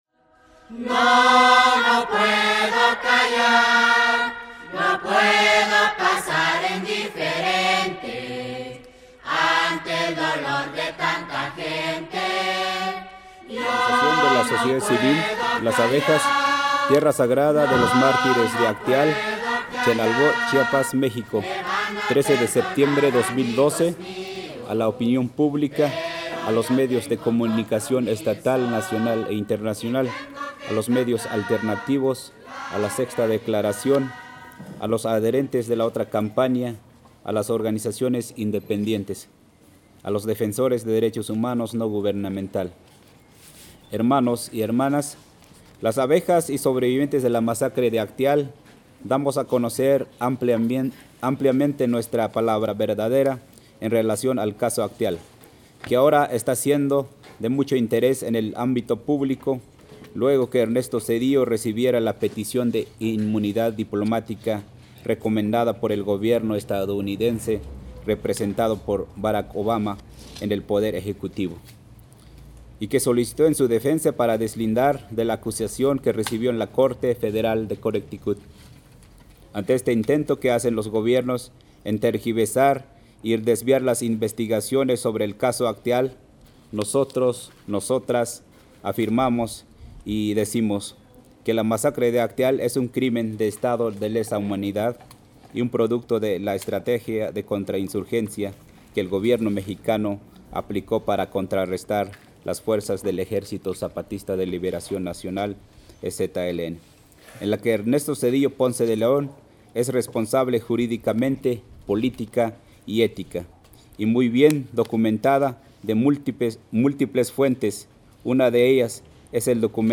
Conferencia de prensa en el Frayba